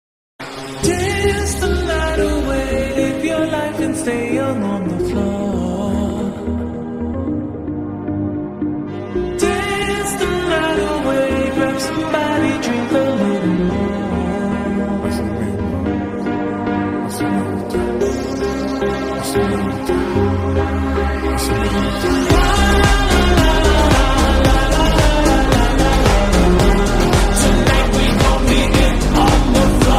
Dance , pop